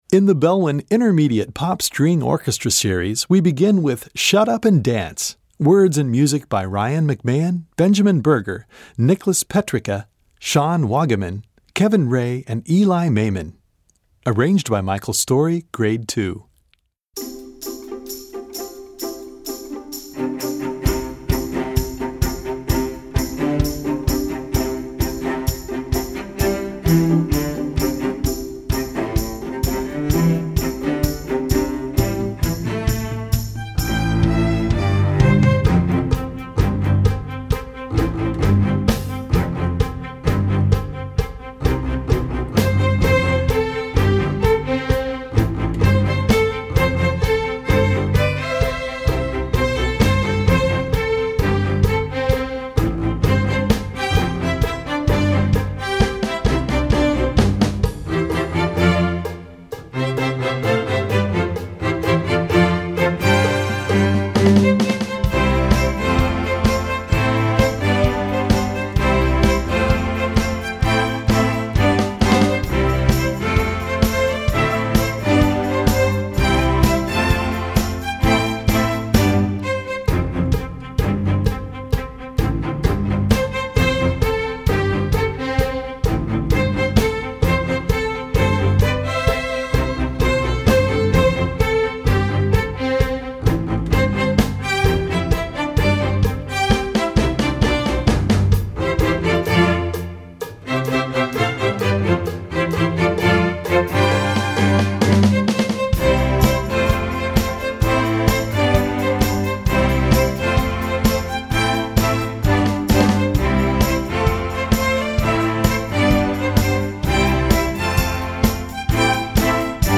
Gattung: Streichorchester
Besetzung: Streichorchester